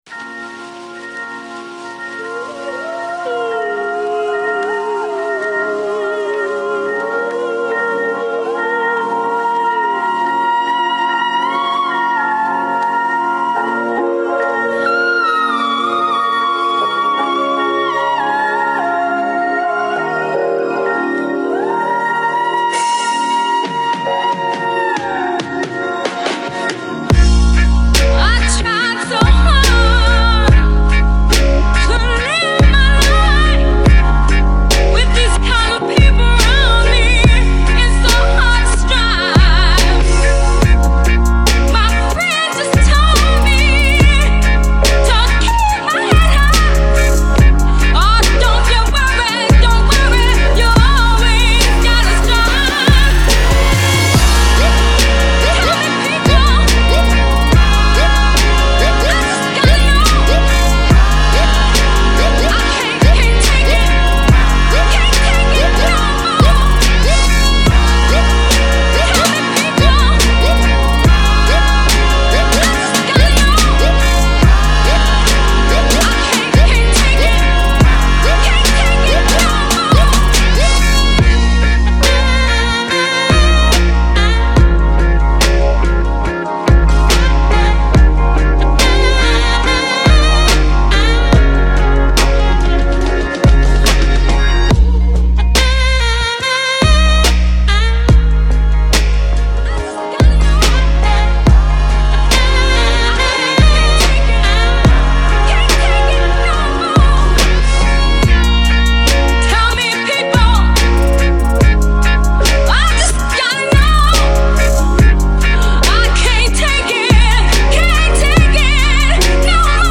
Heavily sampling an obscure 70's Polish soul cut